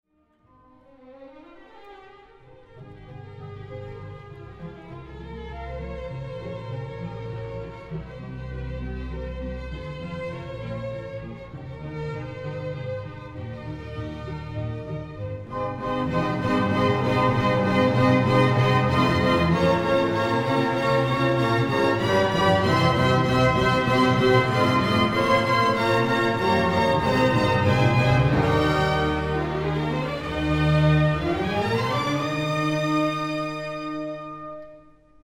Brzmienie zespołu jest cienkie, przejrzyste i pozwala z łatwością wydobyć mnóstwo detali, które umykają zazwyczaj w masywnym brzmieniu standardowej orkiestry.
Posłuchajcie dobrze Wam już znanego miejsca i zwróćcie uwagę jak rozkołysana i śpiewna jest szybko potraktowana druga część:
Sir John Eliot Gardiner, Orchestre Révolutionnaire et Romantique, 1994, I – 6:30 (P), II – 8:15, III – 7:12 (P), IV – 9:57 (P) [31:54], Deutsche Grammophon